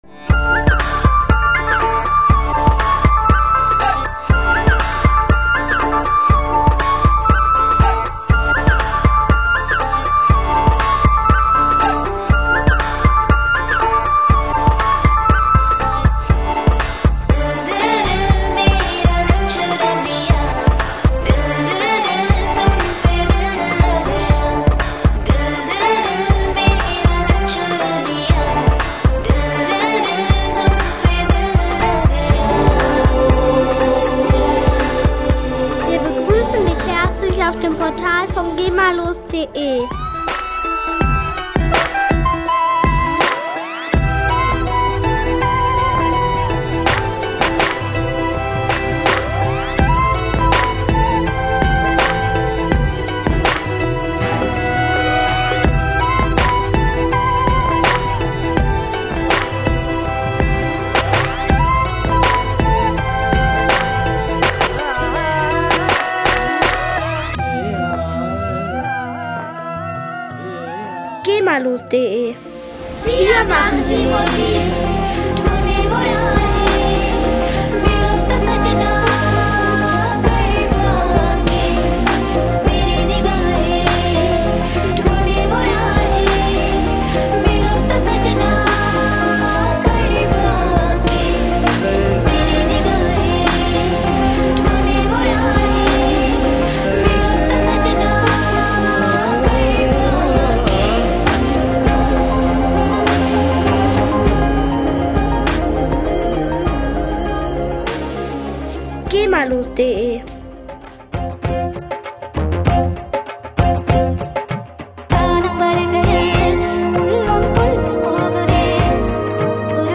gemafreie CD
Musikstil: Bollywood